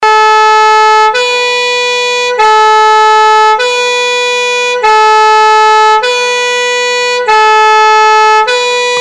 firefighter-siren_25138.mp3